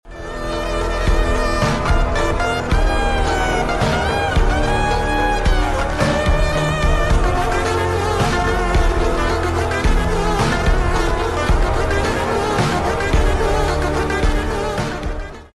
Funk Phonk